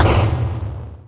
Techmino/media/effect/chiptune/clear_2.ogg at fd3910fe143a927c71fbb5d31105d8dcaa0ba4b5